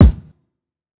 TS Kick_7.wav